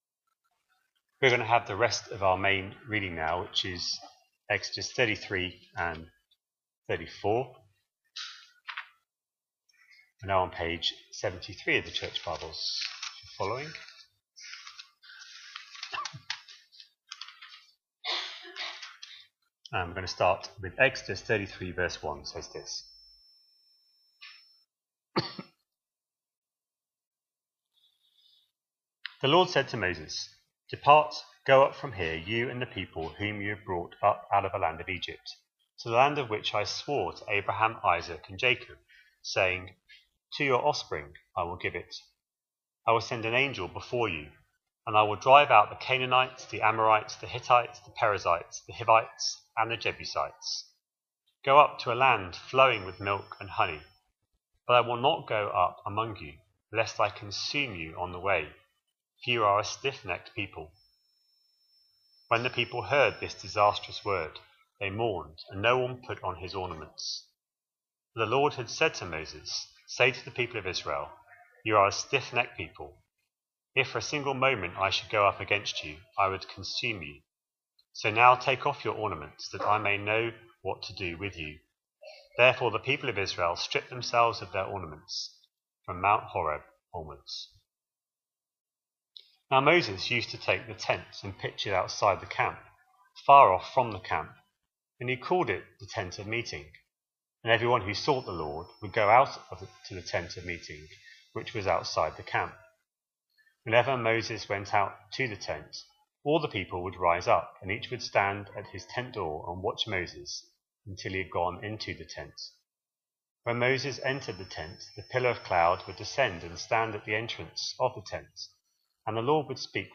A sermon preached on 21st September, 2025, as part of our Exodus series.